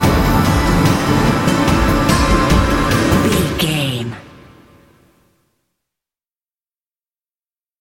Thriller
Aeolian/Minor
strings
drums
cello
violin
percussion
tension
ominous
dark
suspense
haunting
creepy